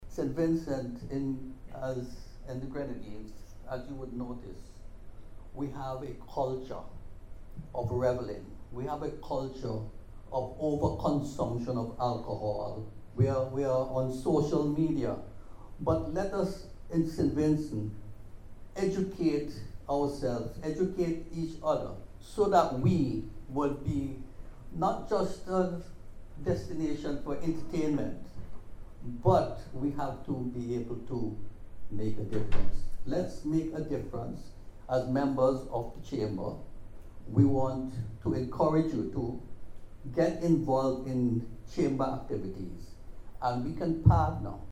He was speaking at the recent launch and signing ceremony of a partnership agreement between DeVry University and the Chamber of Industry and Commerce, aimed at expanding access to higher education through the Bridge to Brilliance Initiative.